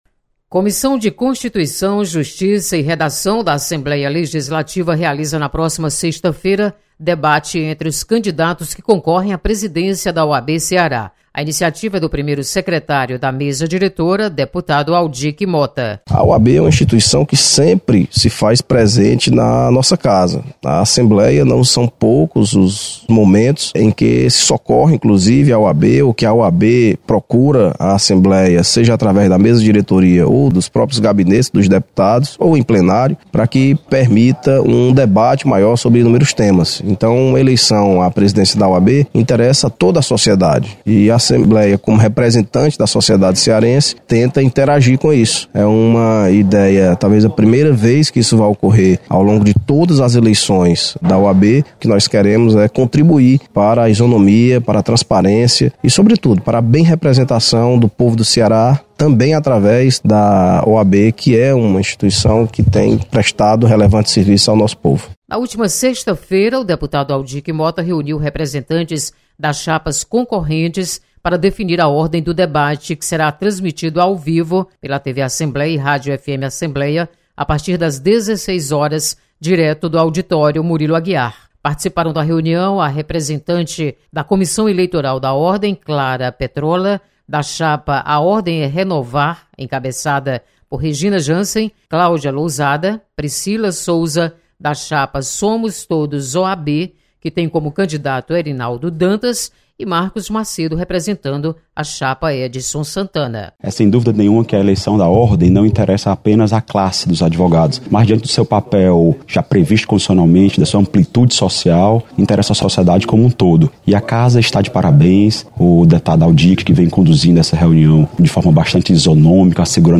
Assembleia vai realizar debate entre candidatos à presidência da OAB-CE. Repórter